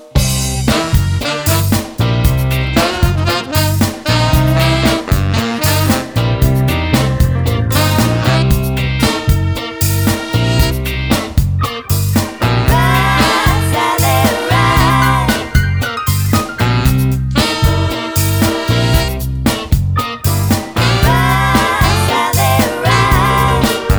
Medley - Full Songs Soundtracks 5:49 Buy £1.50